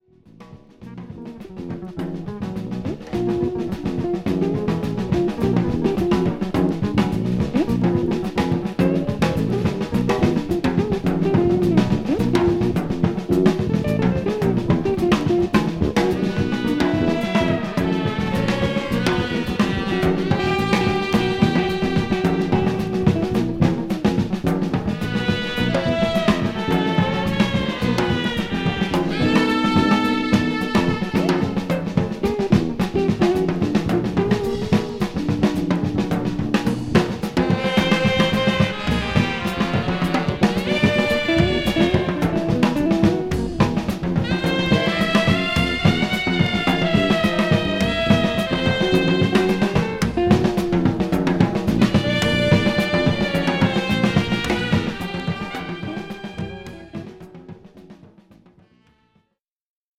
JAZZ / JAZZ FUNK / FUSION
ロック、ブルース、NW、ファンクなど